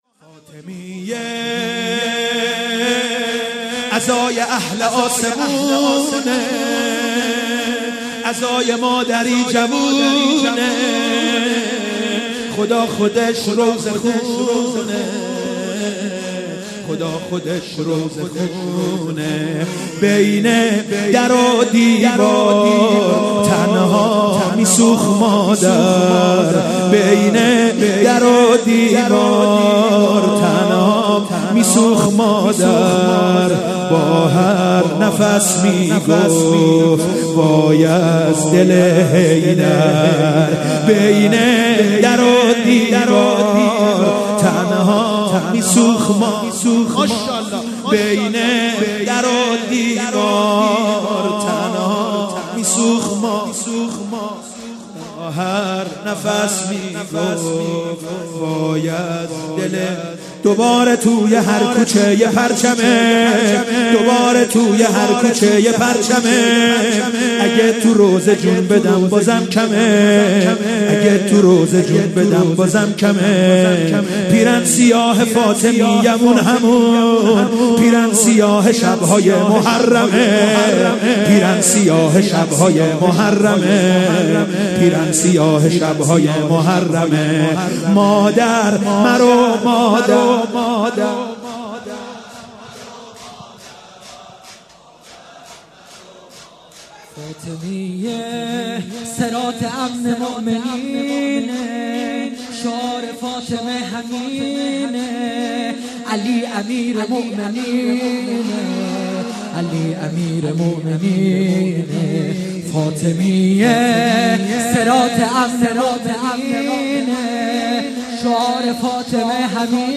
شور مداحی